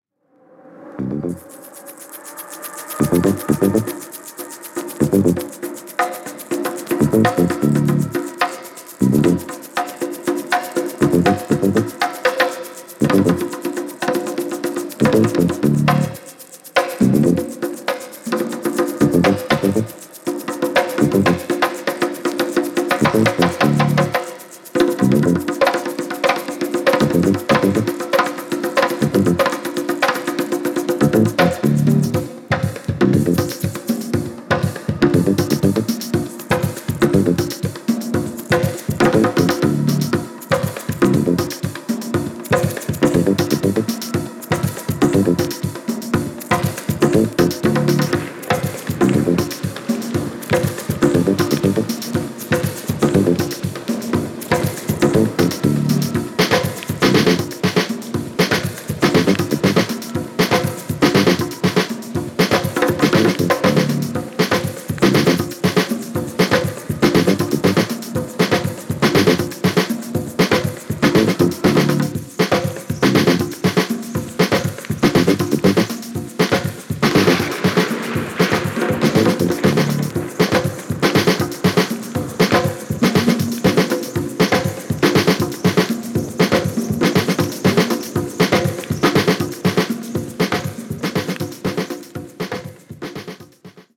Jazzy House！！！